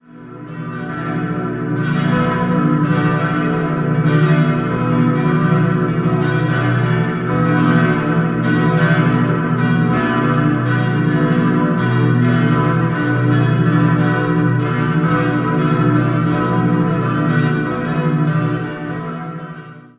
5-stimmiges Geläute: h°-d'-e'-fis'-a' Die kleine Glocke wurde 1912 von Karl Hamm in Regensburg gegossen, die vier anderen sind Werke der Firma Perner aus Passau aus dem Jahr 1949.